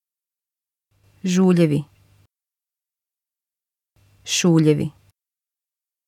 U krijgt per keer 2 woorden achter elkaar te horen. Vul hieronder in welke klank u hebt gehoord.